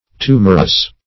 Tumorous \Tu"mor*ous\, a. [L. tumorosus inflated.]